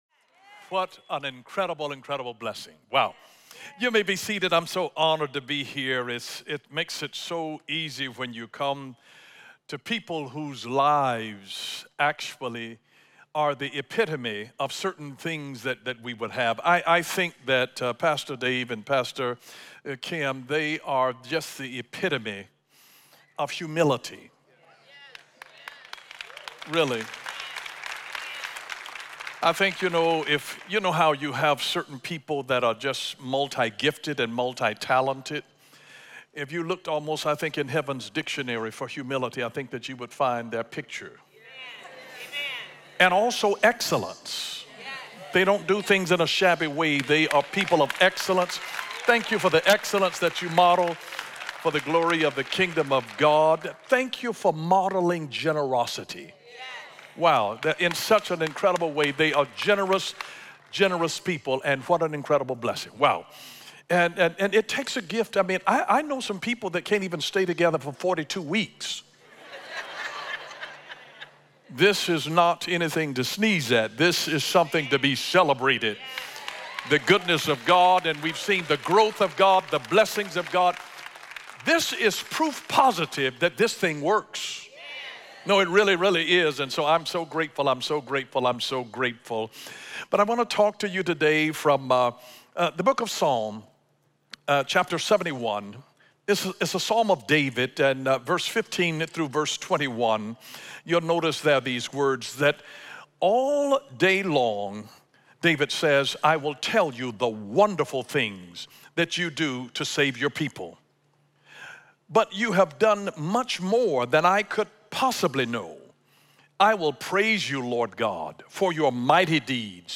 Guest Speaker: Bishop Dale Bronner